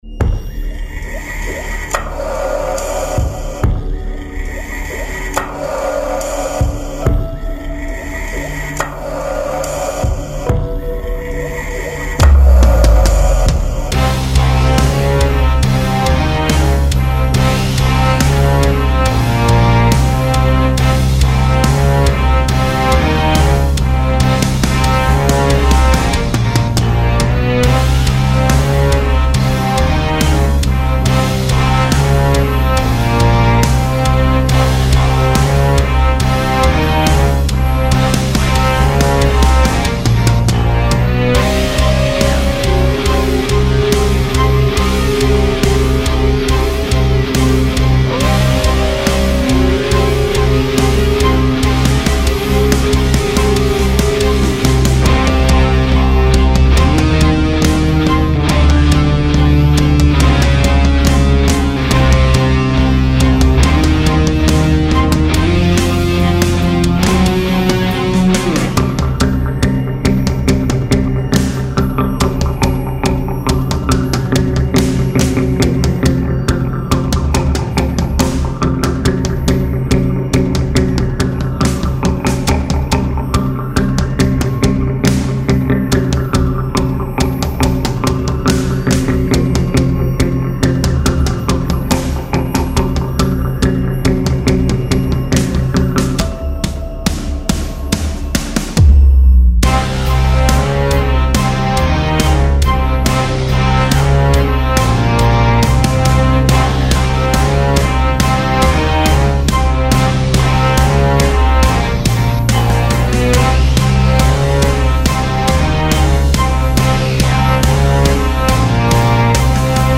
恐怖を煽るための派手さではなく、気づいた時にはすでに詰んでいるそんな状況を音で表現しています。
• BPM：70
• ジャンル：ダークシネマティック